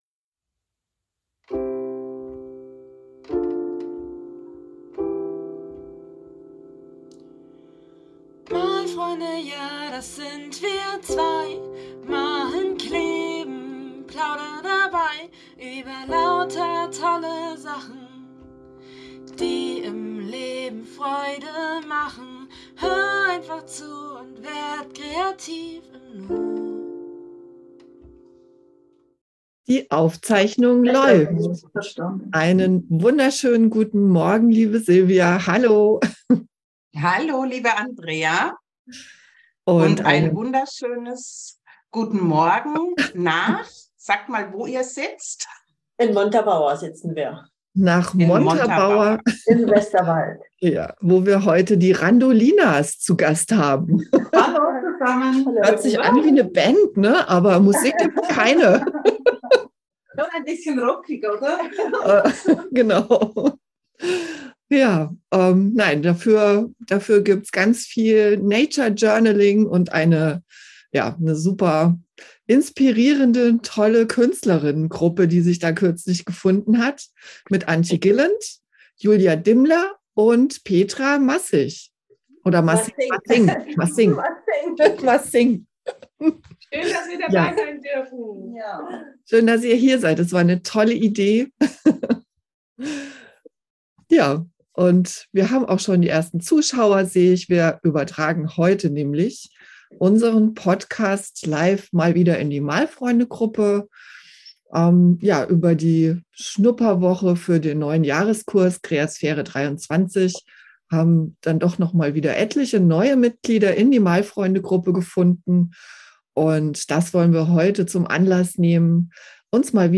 #7/15: Live in der Malfreunde-Gruppe ~ Malfreunde FM - dein Podcast für Kreativität und Lebensfreude Podcast